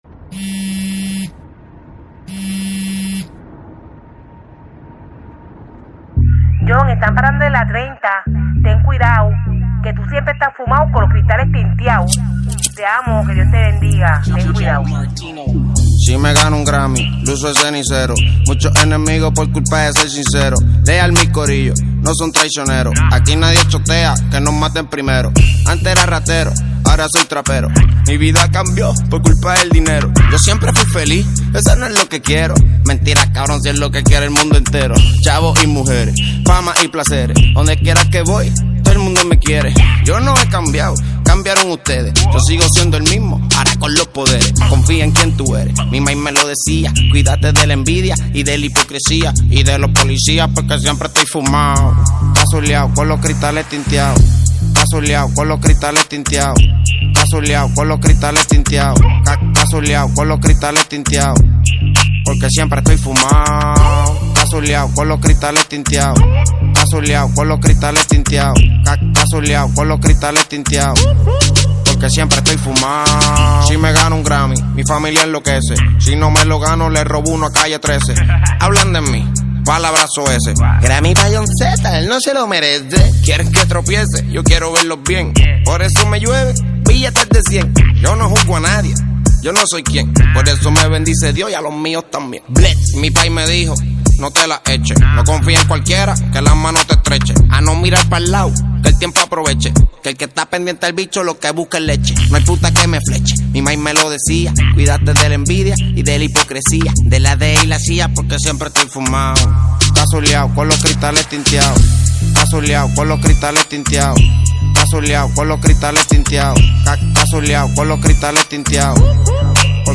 Carpeta: Reggaeton y + mp3